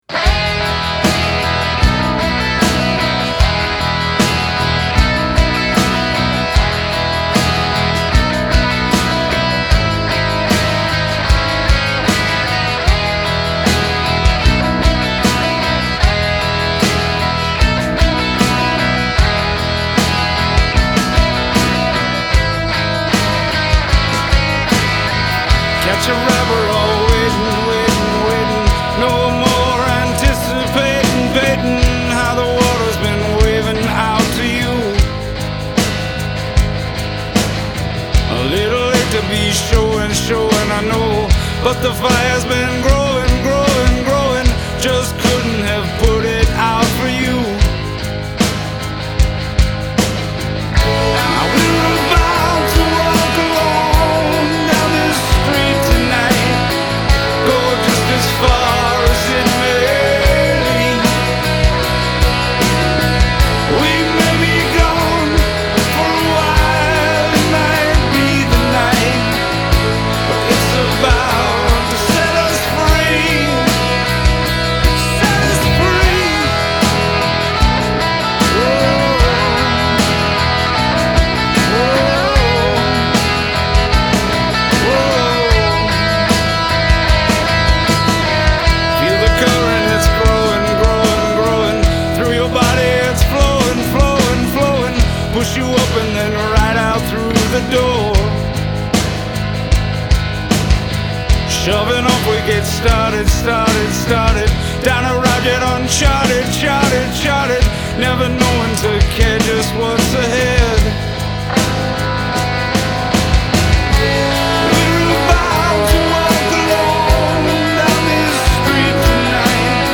Here is an original song that our band tracked in a local studio.Just recently got an mp3 from him.Think I listened to it too much and would like some fresh ears and opinions on the mix and the song if possible .thanks.